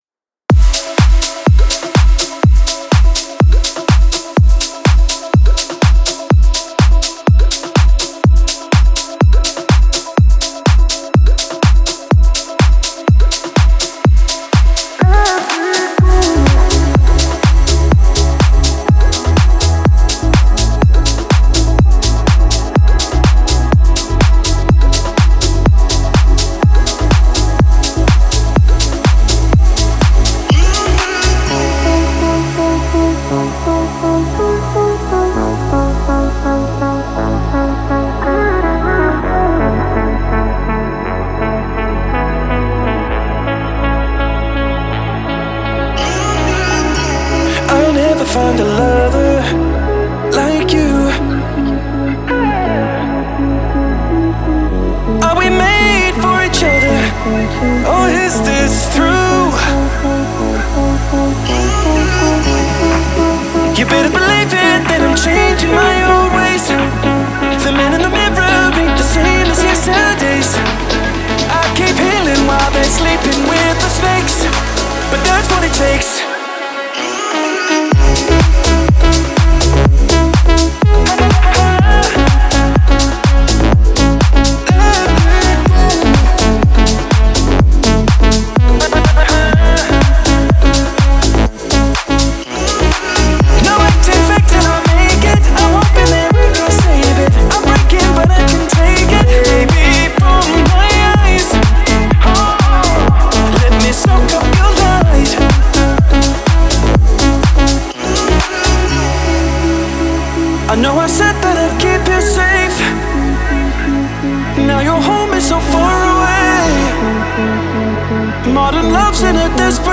Стиль: Progressive House